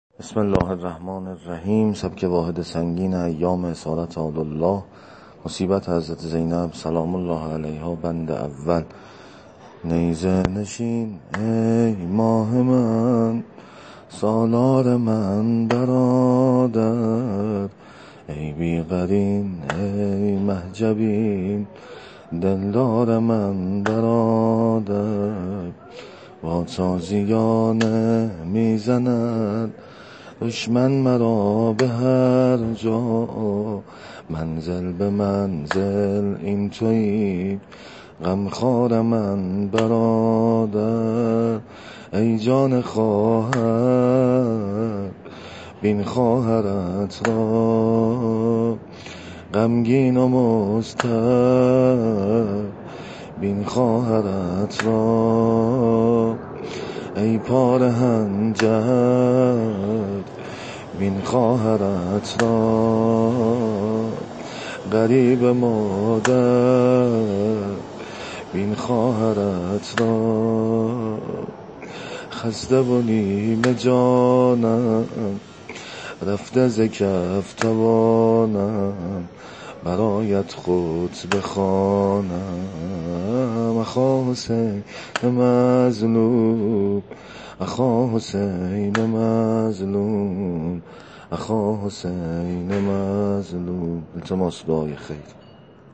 سبک واحد سنگین ایام اسارت آل الله مصیبت حضرت زینب سلام الله علیها -(نیزه نشین ای ماه من سالار من برادر)